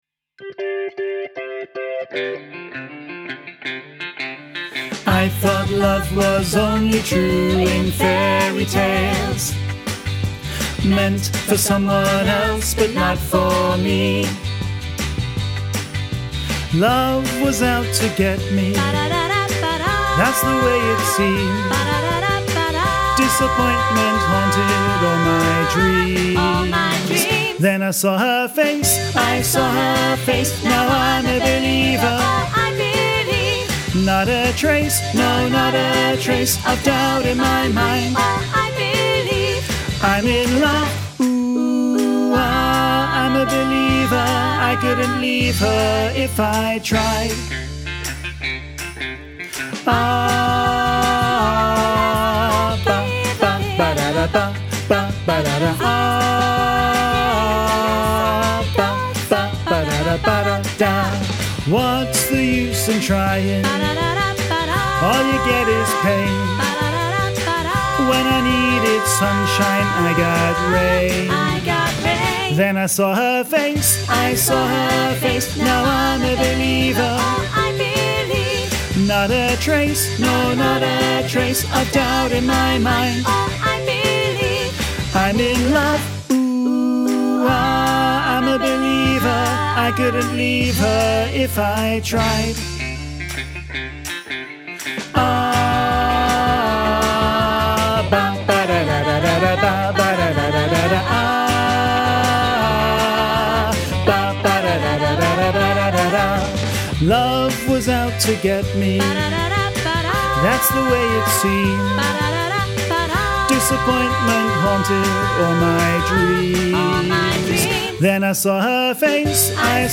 Training Tracks for I'm a Believer
Listen to bass track with soprano and alto accompaniment
im-a-believer-bass-half-mix.mp3